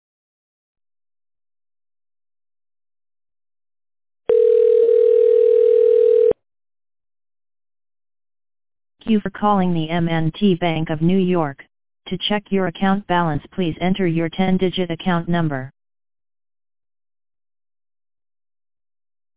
It’s a terrible recording and not the same as any genuine M&T bank numbers or voices